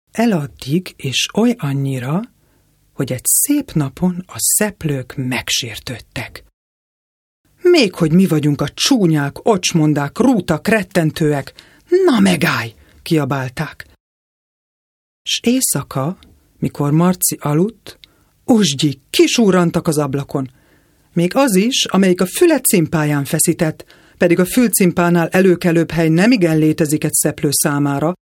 Kein Dialekt
Sprechprobe: Werbung (Muttersprache):
hungarian female voice over artist